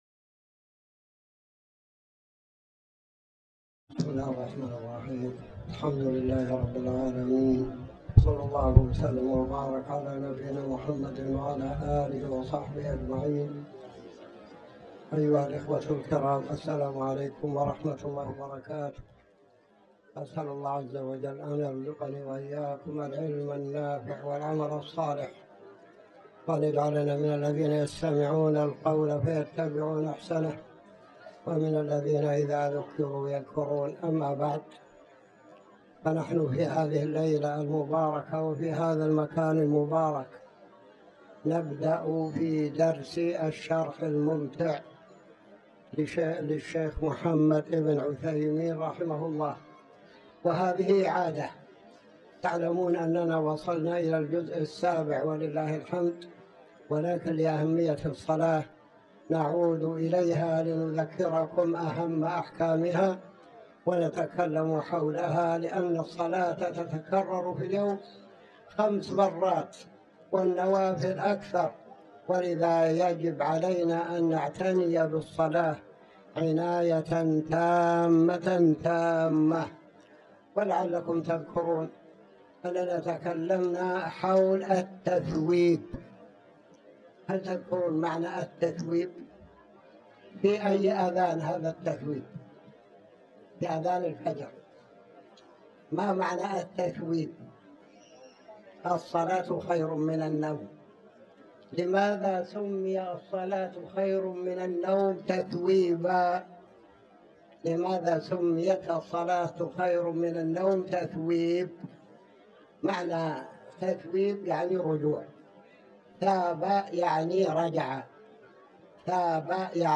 تاريخ النشر ٤ جمادى الآخرة ١٤٤٠ هـ المكان: المسجد الحرام الشيخ